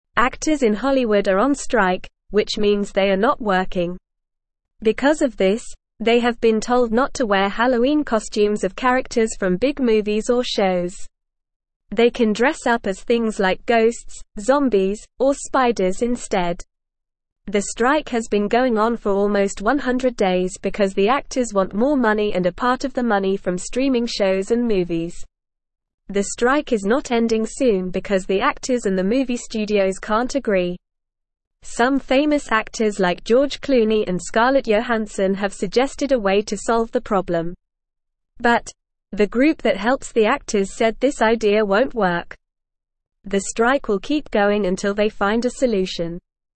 Normal
English-Newsroom-Beginner-NORMAL-Reading-Hollywood-Actors-on-Strike-No-Movie-Costumes-Allowed.mp3